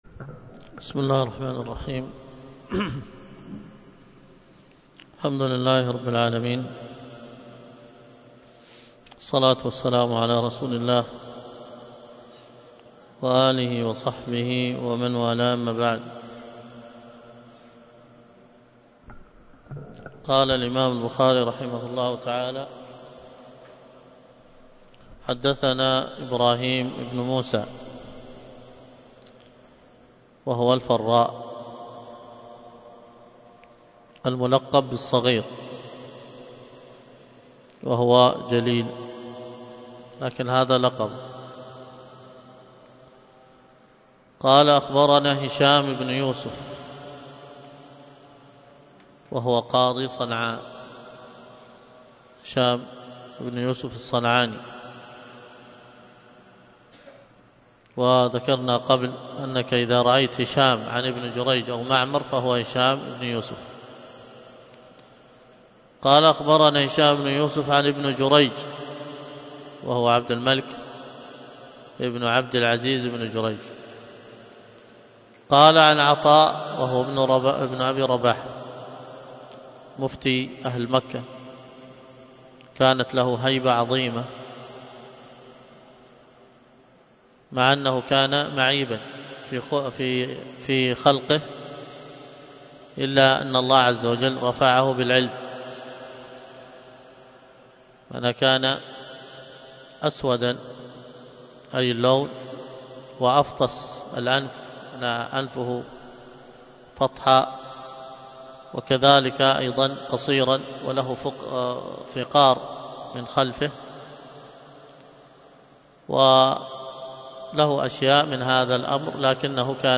الدرس في كتاب التفسير من صحيح البخاري 243